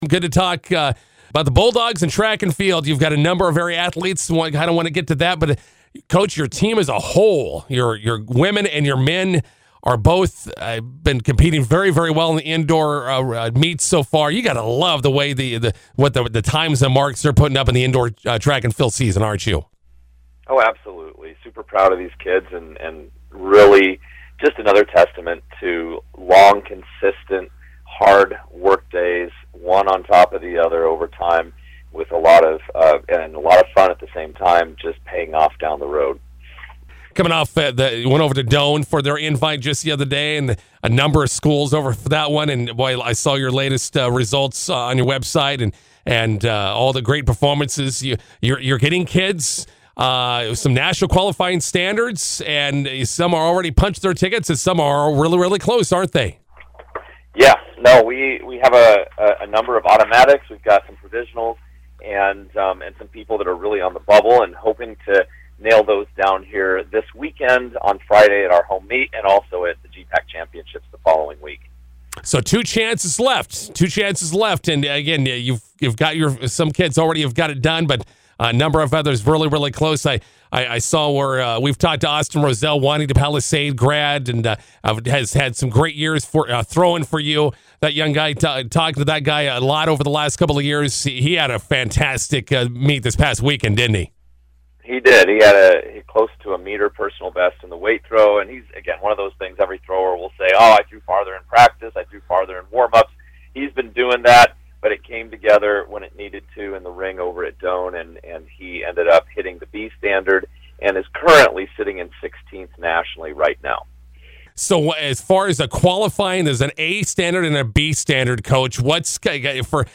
INTERVIEW: Concordia Track and Field indoor teams ranked among the top 15 in the nation.